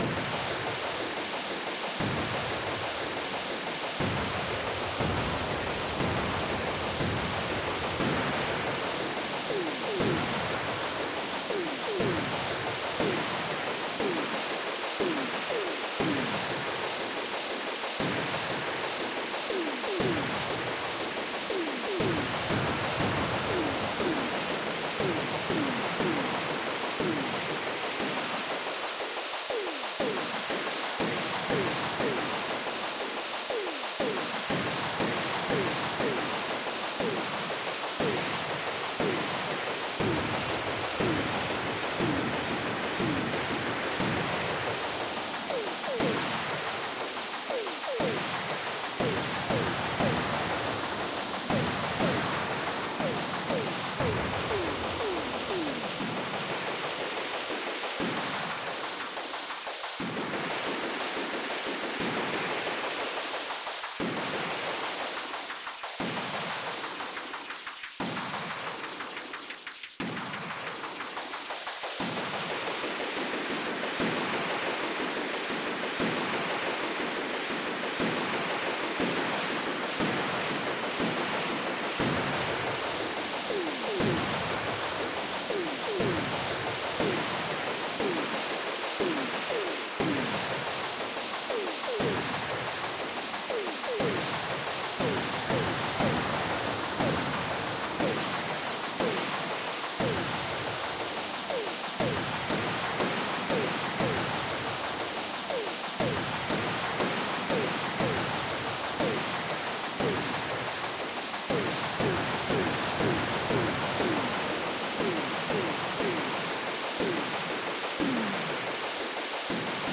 MIDI Music File
General MIDI